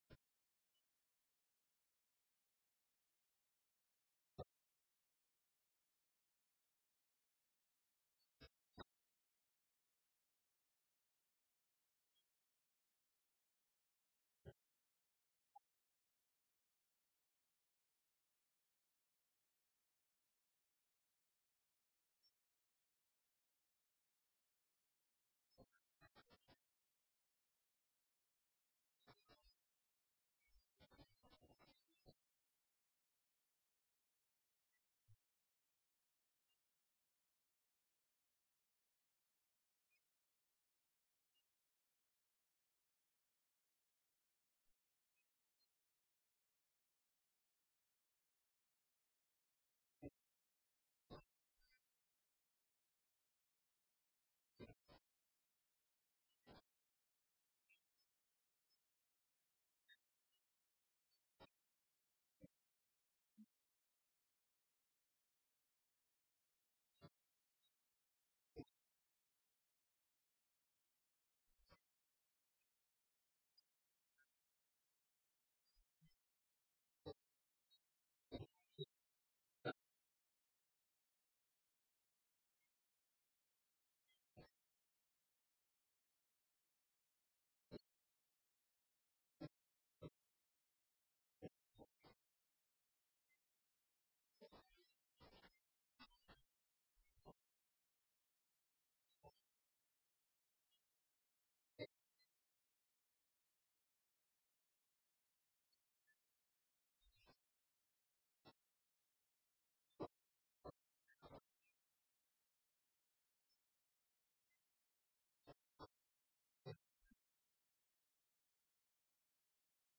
الدرس الخامس والتسعون بعد المائة الأولى